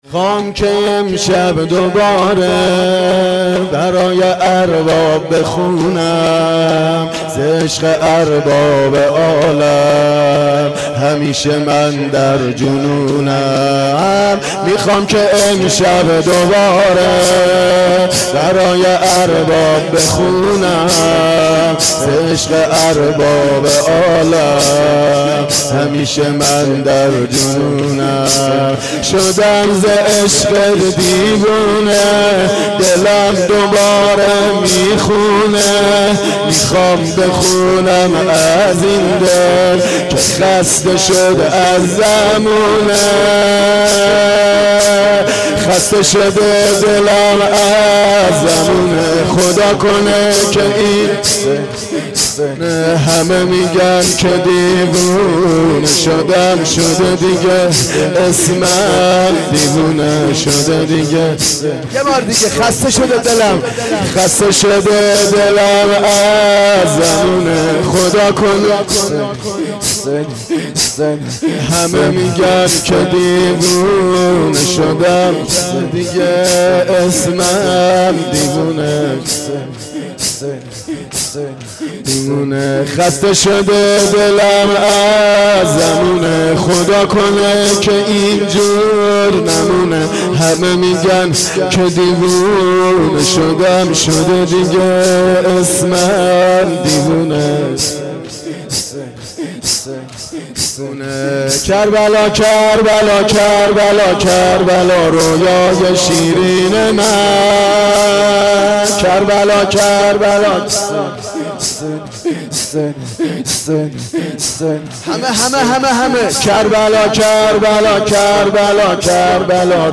مداح